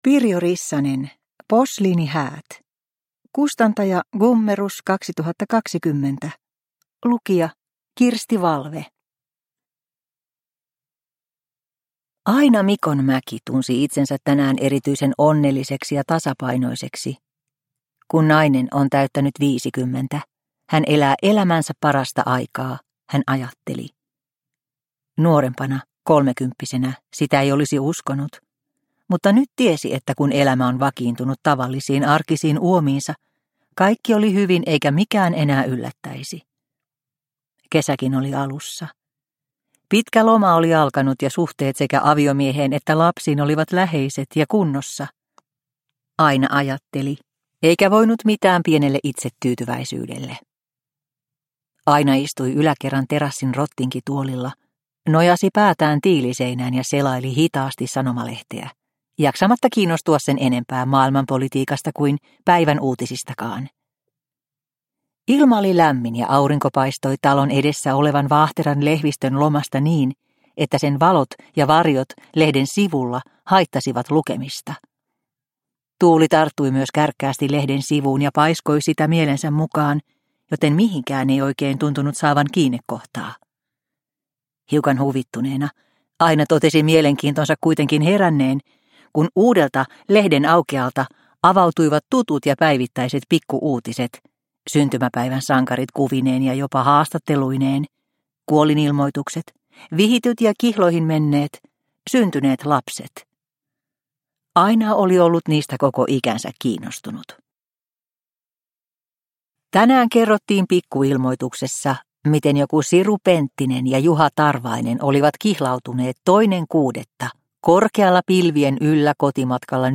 Posliinihäät – Ljudbok – Laddas ner